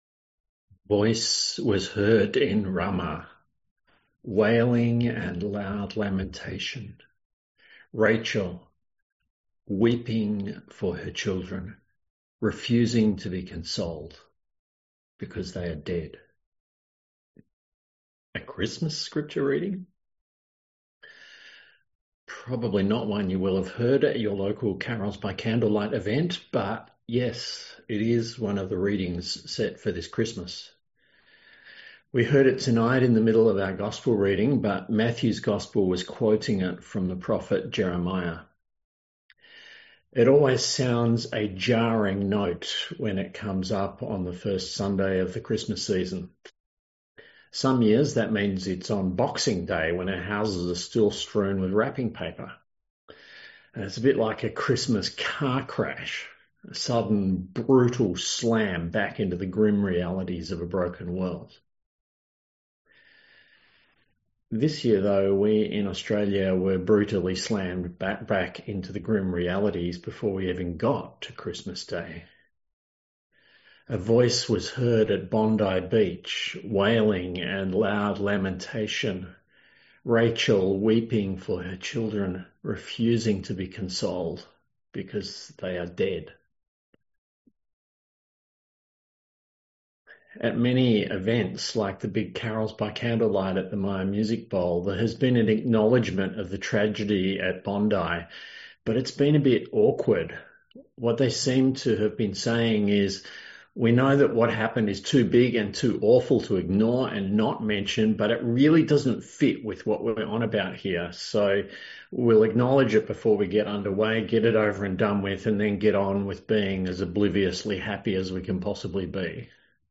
A sermon on Matthew 2:16-18